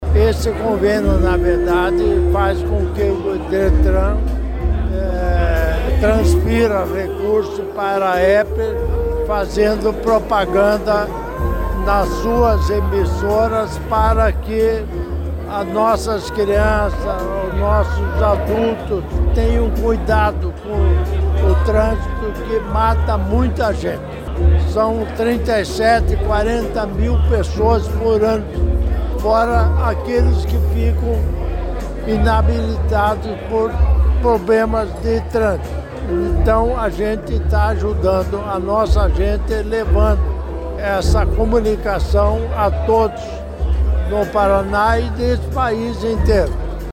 Sonora do governador em exercício Darci Piana sobre o convênio do Estado com a AERP